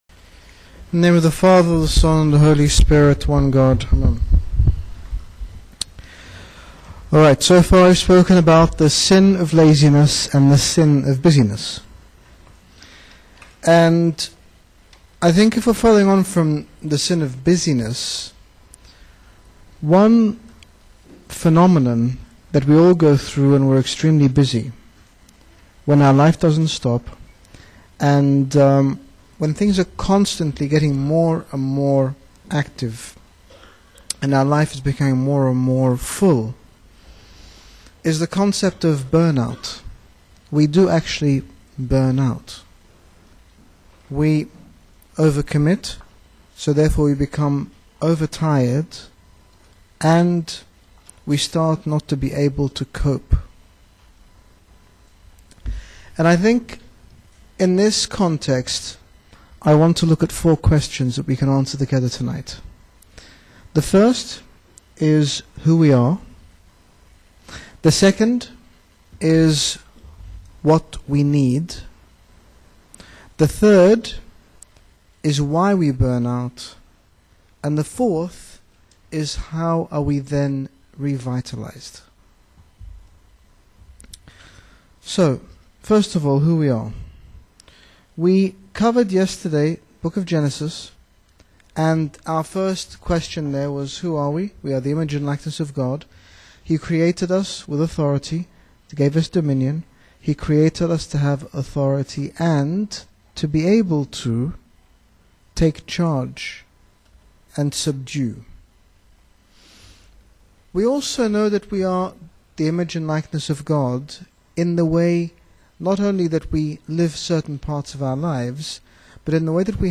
Lecture 3 of a 4 part series on Battling Burnout/Keeping Balance that was presented by H.G. Bishop Angaelos at the 2009 Midwest Fall Retreat.